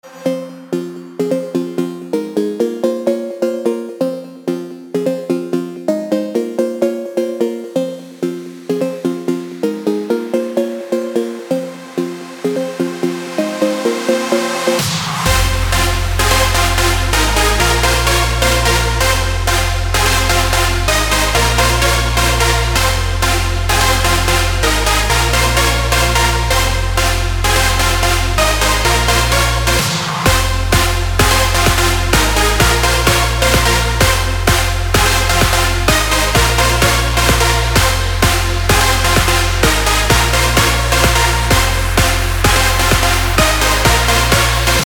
• Качество: 256, Stereo
красивые
без слов
club